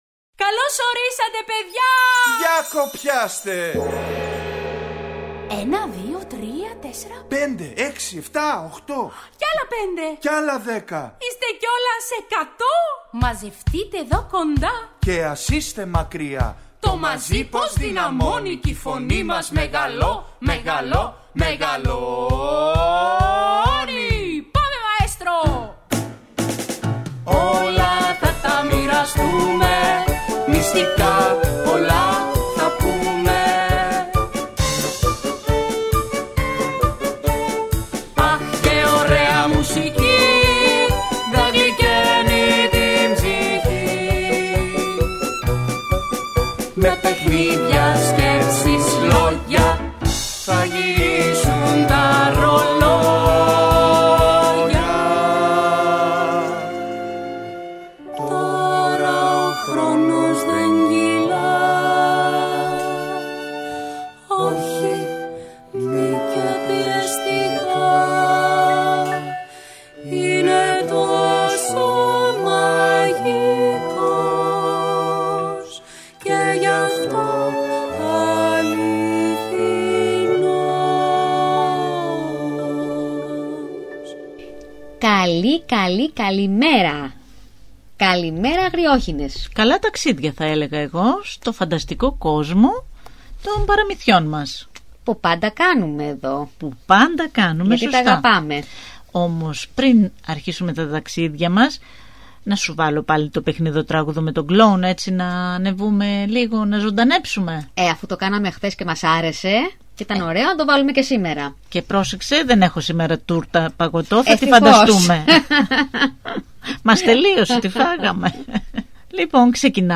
Ακούστε στην παιδική εκπομπή ‘’Οι Αγριόχηνες’’ το παραμύθι “Tο ταξίδι” της Σάνα Φραντζέσκα (ΦΡΕΝΤΣΙ)